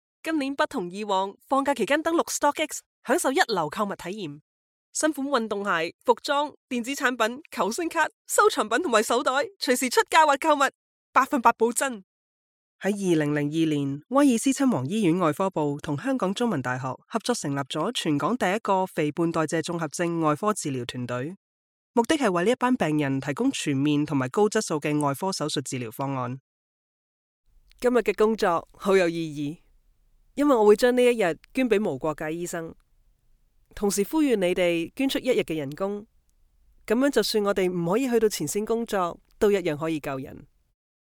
Commercial Demo
Her warm female tone is great for both professional narrations and positive representation for your brand!
DeepHighMezzo-SopranoSoprano